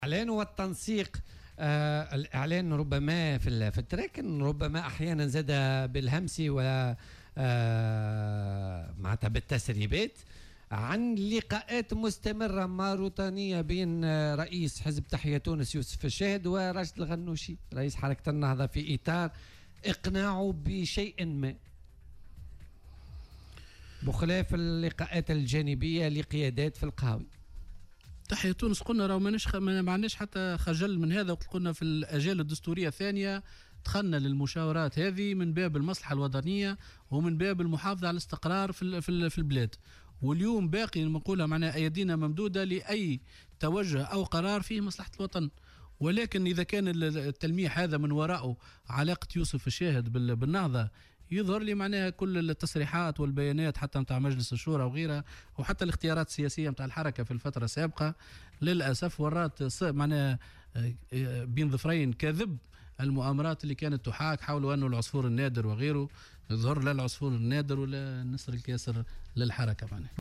في مداخلة له اليوم في برنامج"بوليتيكا" على "الجوهرة أف أم"